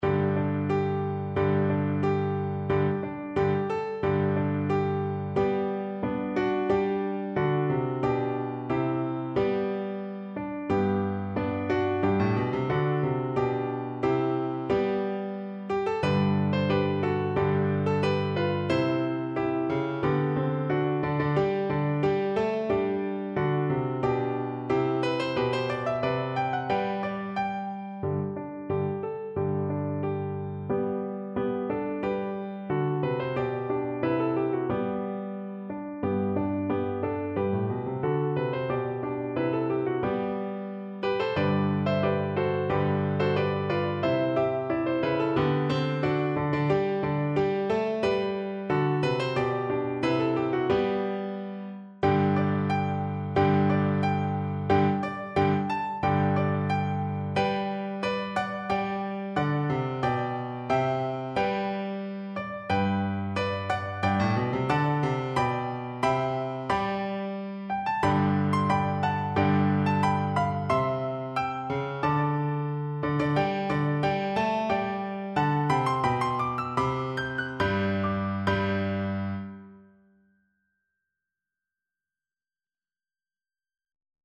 Play (or use space bar on your keyboard) Pause Music Playalong - Piano Accompaniment Playalong Band Accompaniment not yet available reset tempo print settings full screen
Viola
G major (Sounding Pitch) (View more G major Music for Viola )
2/2 (View more 2/2 Music)
March ( = c. 90)
Traditional (View more Traditional Viola Music)